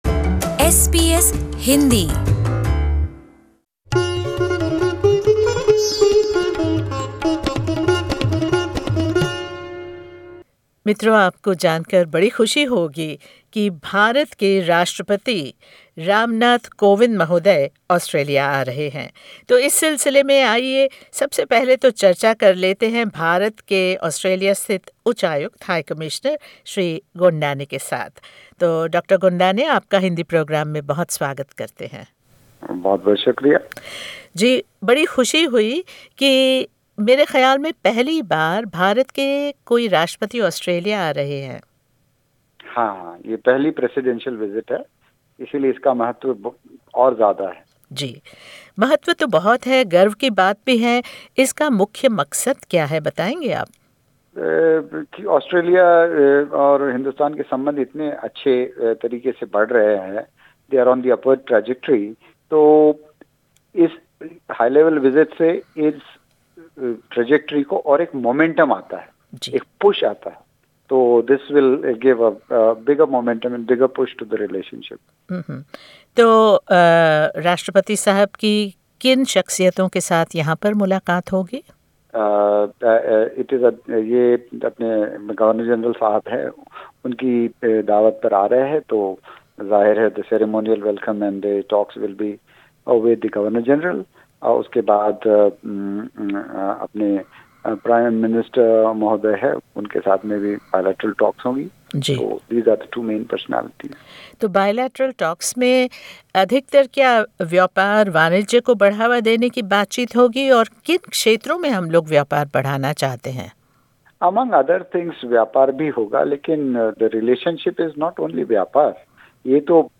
As the excitement over the very first visit by an Indian president reaches a peak, SBS Hindi spoke to Dr A Gondane the Indian High Commissioner in Australia, about the expectations around President Ram Nath Kovind's visit.